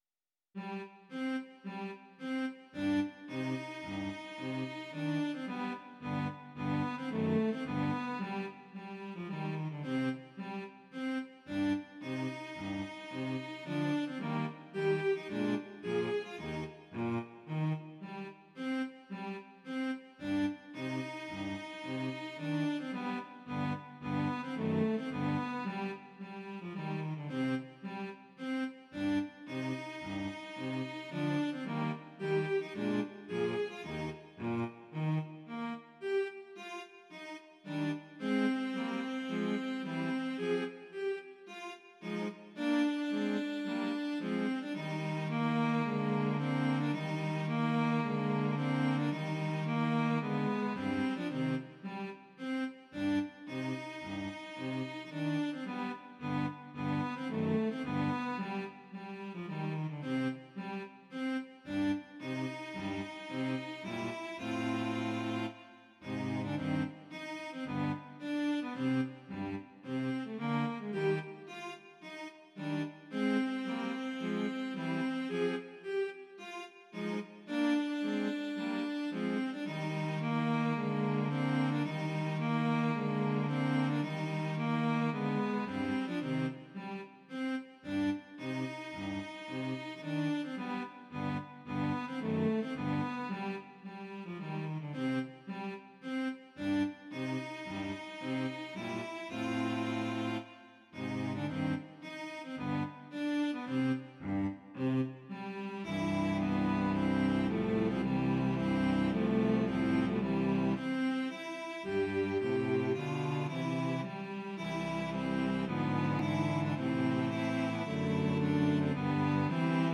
Cello Quartet in C minor